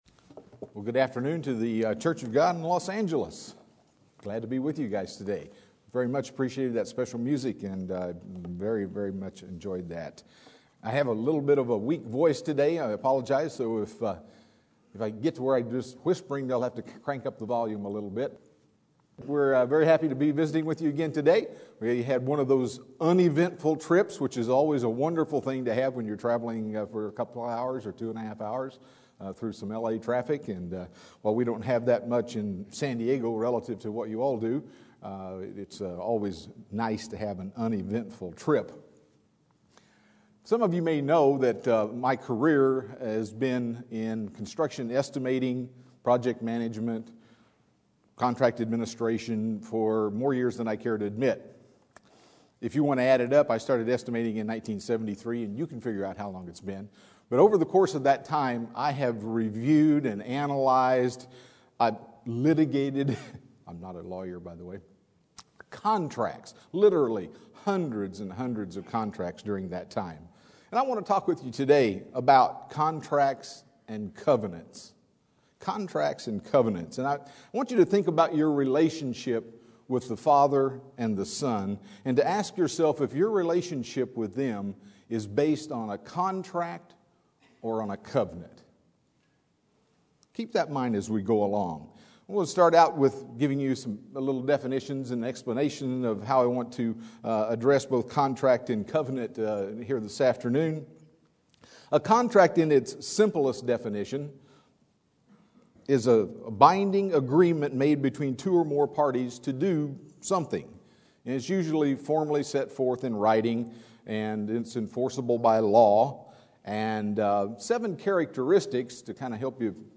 Given in Los Angeles, CA
UCG Sermon Studying the bible?